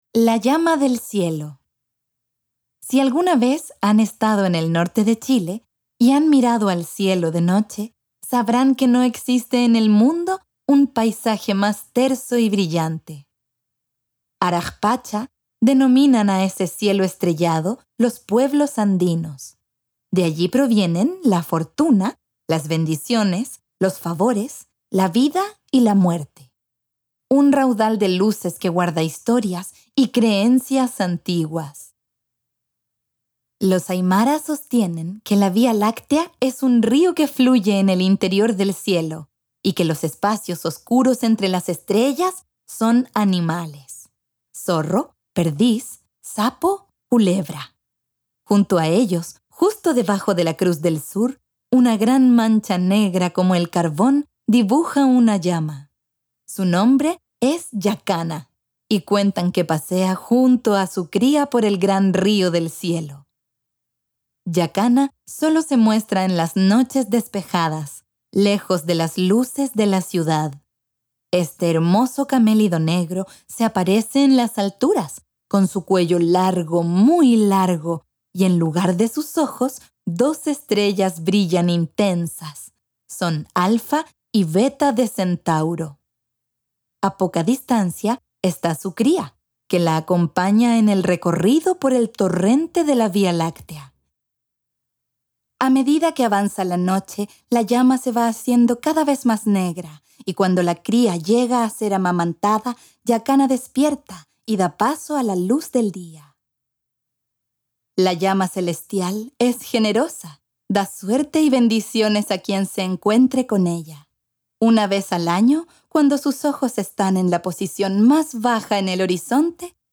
Audiocuentos